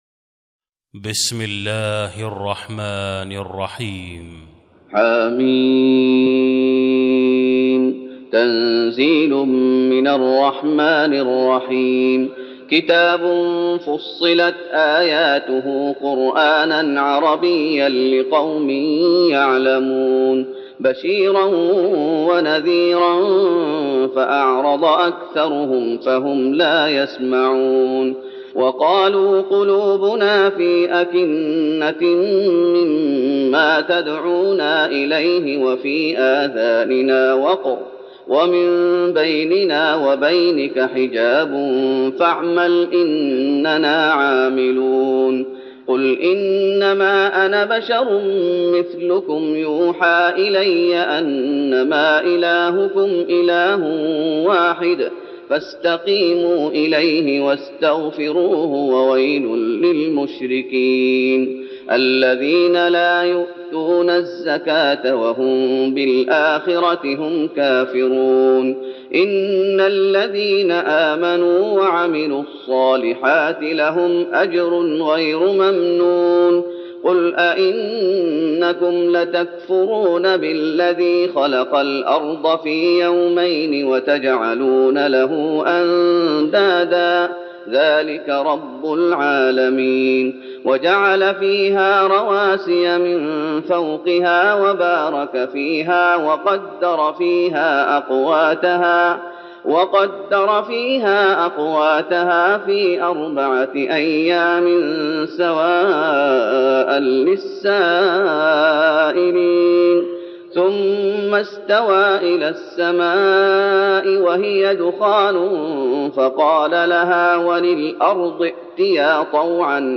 تراويح رمضان 1412هـ من سورة فصلت (1-44) Taraweeh Ramadan 1412H from Surah Fussilat
التراويح - تلاوات الحرمين